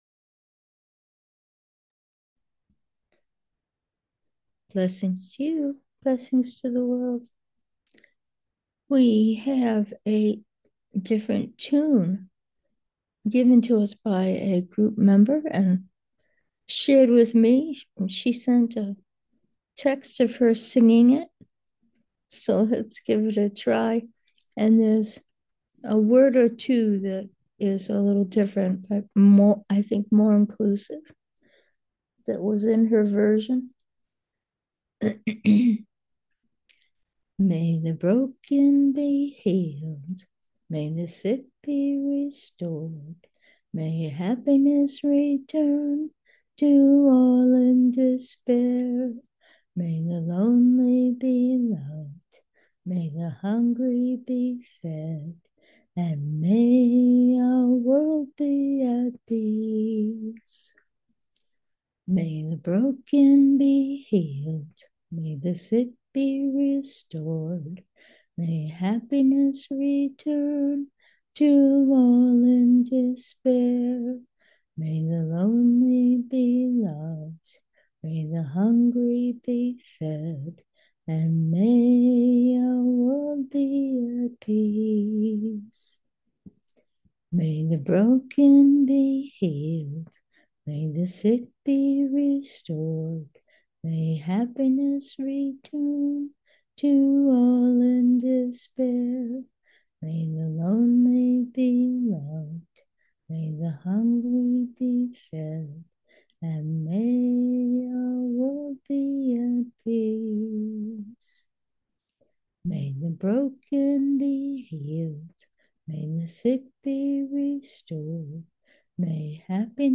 Meditation: conscientiousness 8, new melody
A member of the greater group field sent me a text with her singing the melody she created for the prayer we’ve been using. We used it with this meditation!
conscientiousness-8-new-melody.mp3